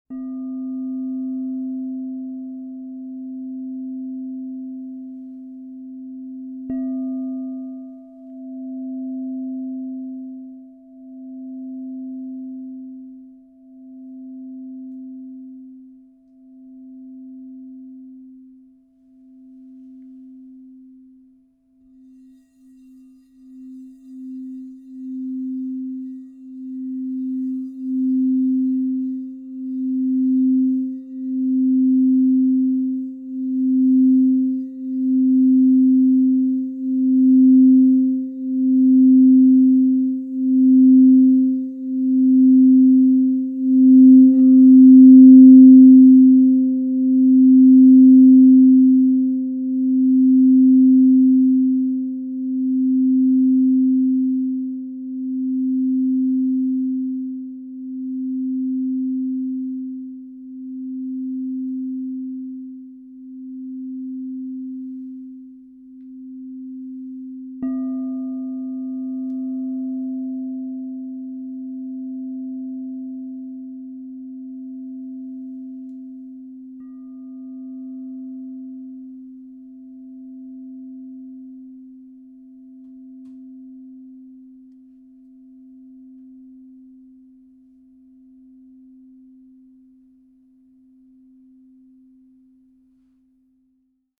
Larimar 10" C -10 Crystal Tones Singing Bowl - Divine Sound
Genuine Crystal Tones® Alchemy Singing Bowl.